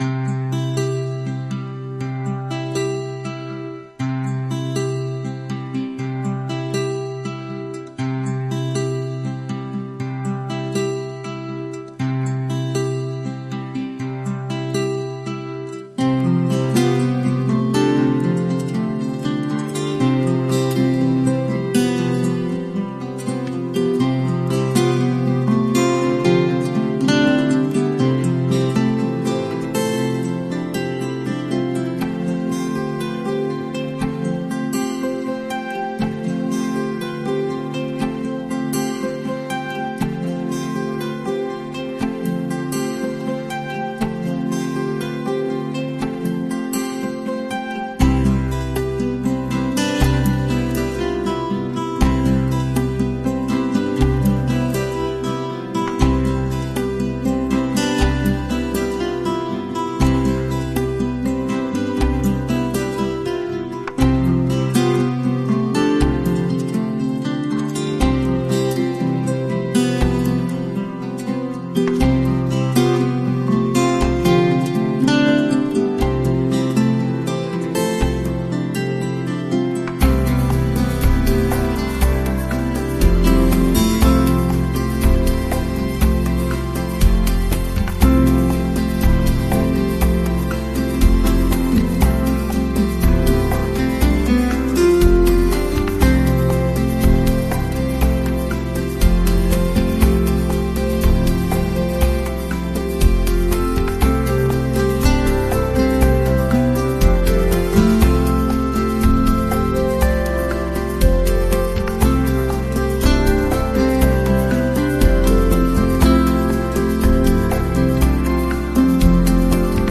このトラックは、穏やかで心地よいボサノバスタイルの音楽を基調にしており、ペットとの癒しの時間を一層豊かにします。
【構成】 ・イントロは、軽やかなアコースティックギターのアルペジオから始まり、ソフトなパーカッションが徐々に加わります。